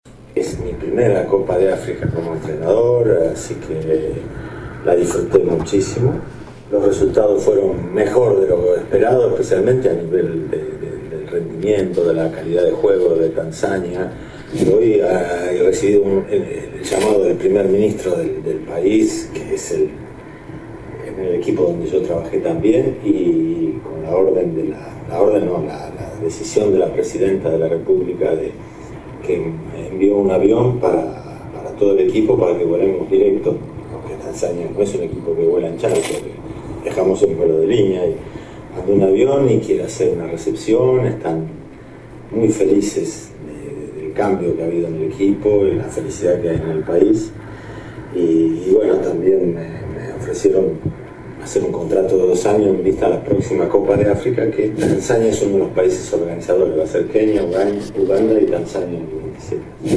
AUDIO DE LA ENTREVISTA CON «OLÉ»